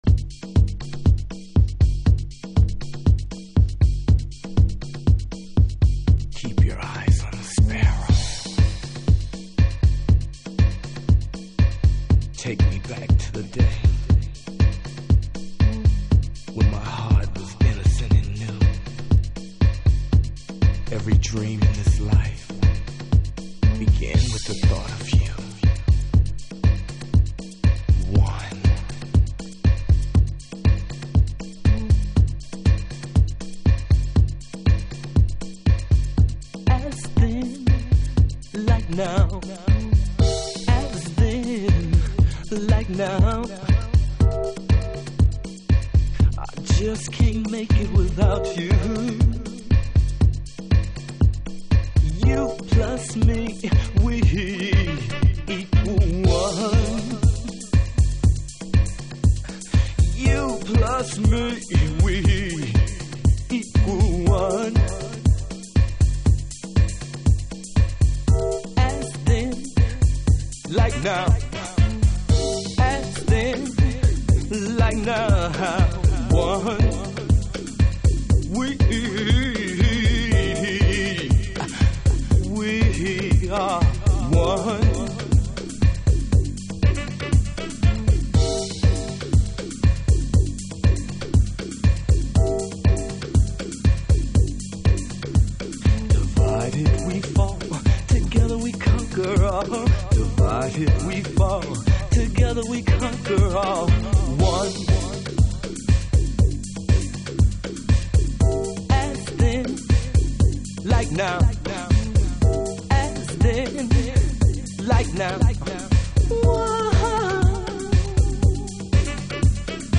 TOP > Chicago Oldschool